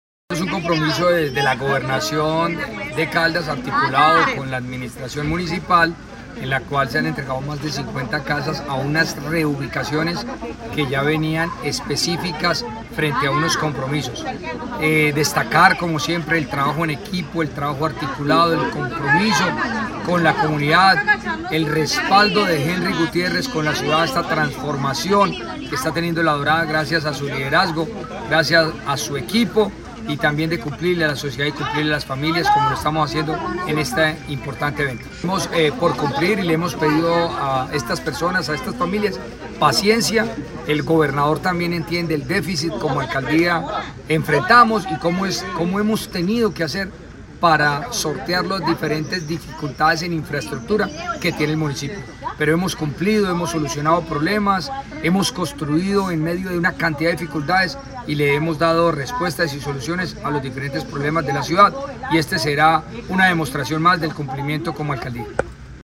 Fredy Saldaña Leopardo, alcalde La Dorada.